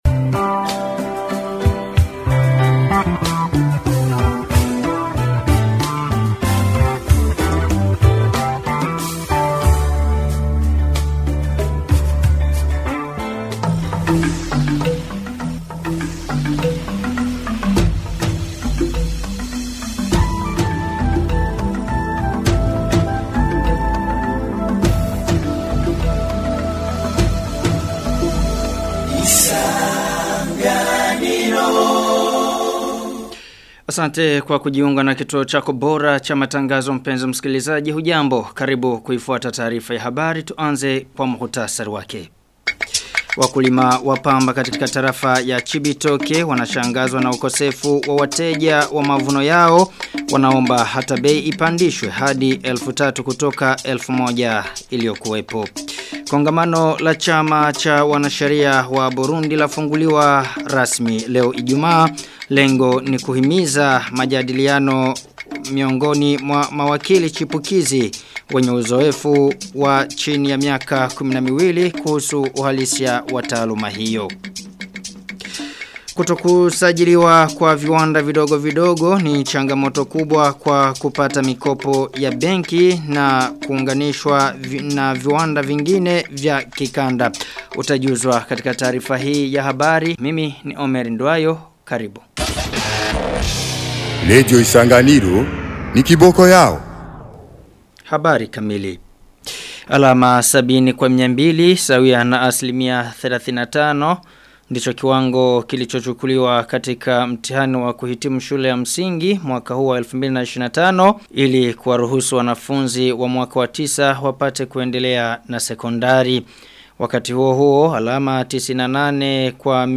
Taarifa ya habari ya tarehe 29 Agosti 2025